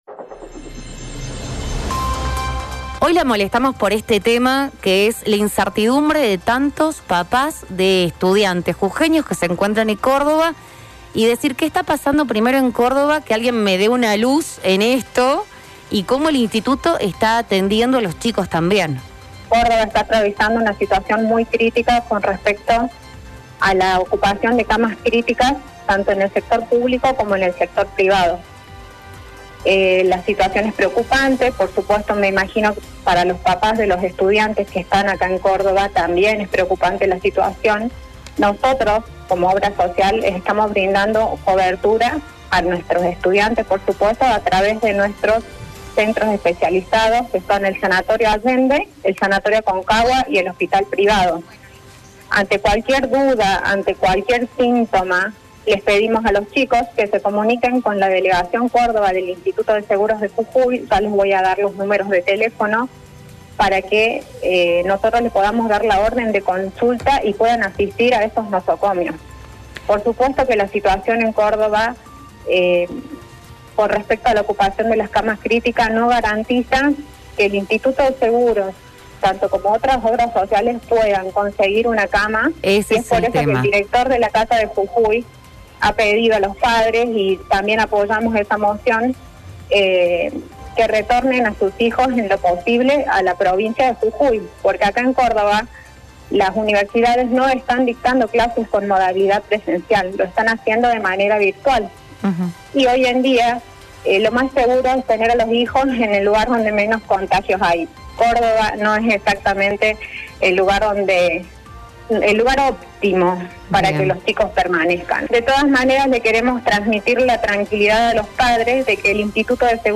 Córdoba está atravesando una situación crítica en el sistema de salud tanto público como privado.